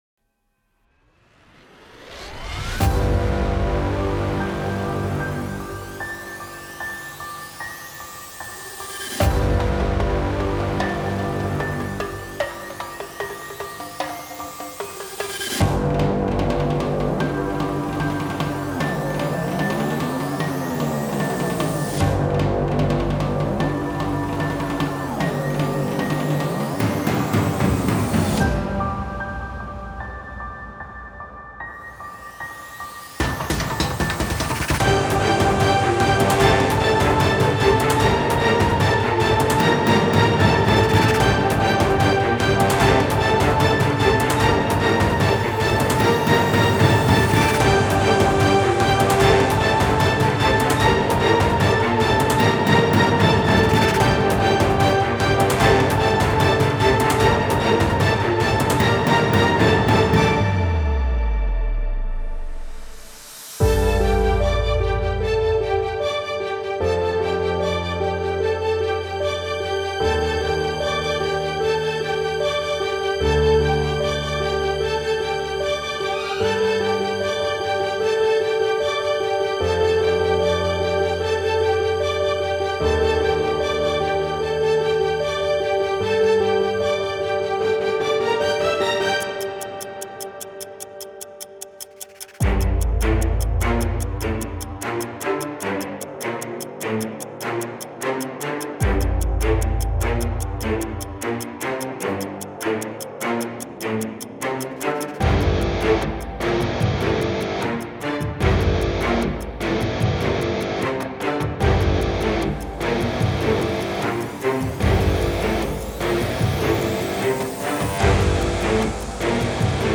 מוזיקת טריילר 🔥🔥🔥בביצוע שלי
וזה מה שיצא ה drums loops של נקסוס (לא אני בניתי את הלופים של התופים) .
משחק יפה עם כל הסינטים, היו קצת קטעים שהם הציקו וחפרו לי אבל זה לא מוריד מהרמה.
כמה הערות שאני ממליץ לך: 0:34 ציפיתי לבס כבד וקולנועי והיה לי מאד חסר ובכללי חסר ברוב הקטע קונטרבסים וצ’לו נמוכים חוץ מכל הבומים והסינטים של בס ברקע.